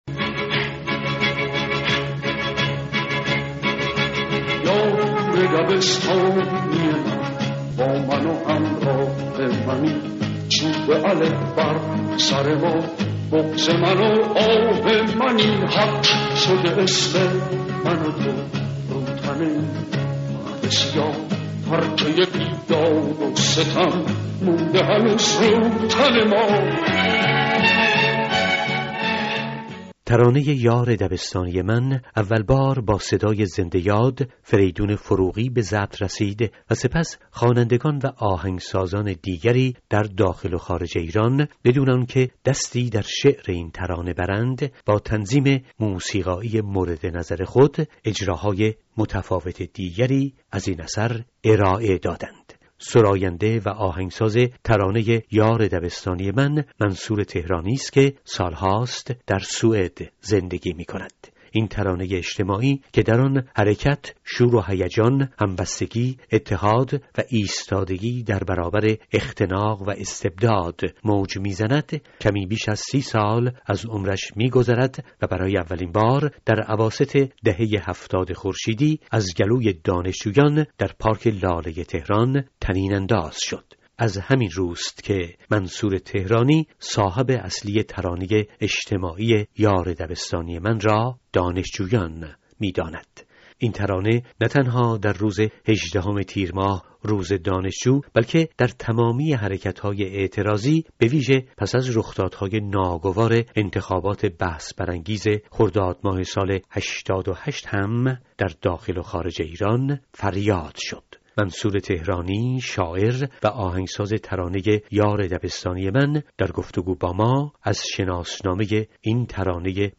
منصور تهرانی، شاعر و آهنگساز این ترانه در گفت‌و‌گو با رادیو فردا از شناسنامه آن می‌گوید و صاحب اصلی این ترانه را دانشجویان ایرانی می‌داند.